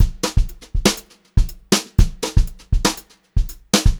120HRBEAT1-L.wav